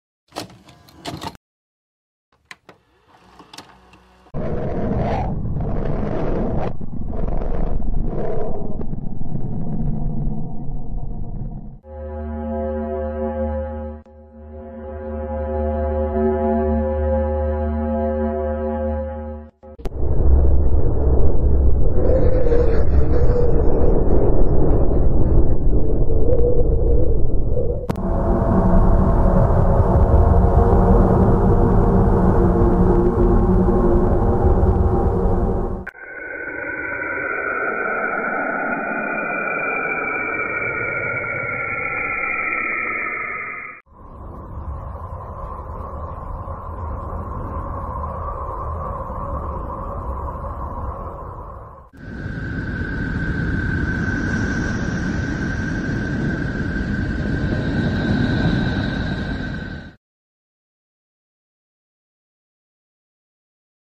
It is possible to hear what the planets in our solar system sound like through planetary phenomenons like magnetic fields and plasma waves, which are then converted into audible sounds for the human ear. These sounds were released by NASA with the help of various satellites scattered throughout our solar system.